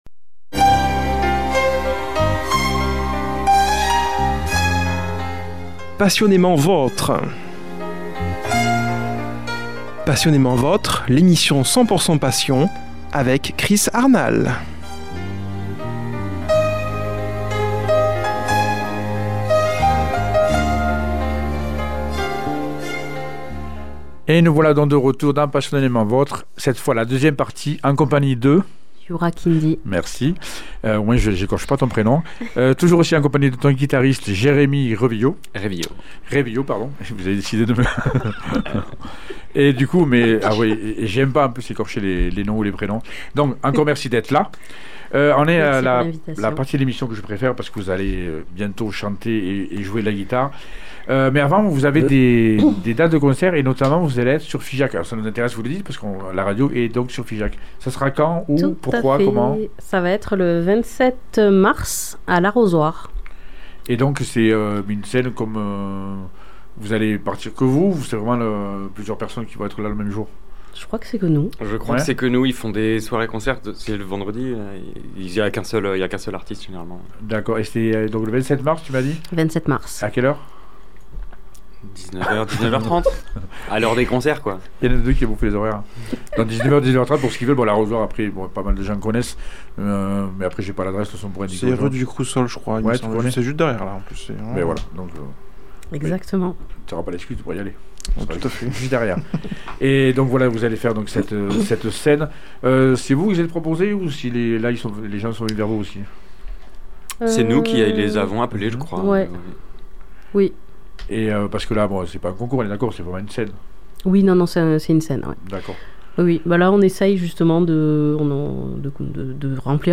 chanteuse à la voix fascinante
guitariste